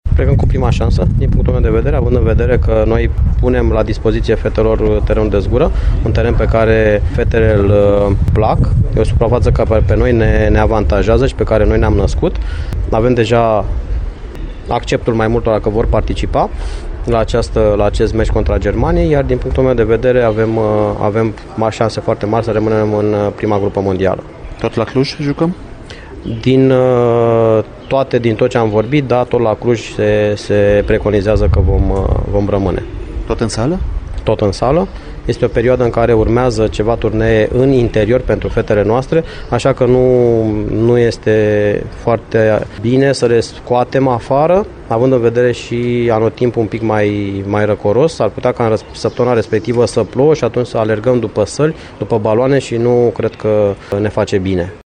Într-un interviu pentru Radio Timișoara